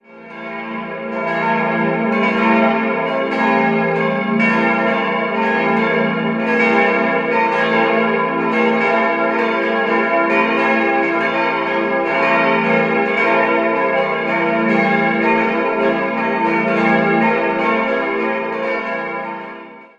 Idealquartett: f'-as'-b'-des'' Alle Glocken wurden im Jahr 1993 von der Gießerei Bachert in Bad Friedrichshall gegossen.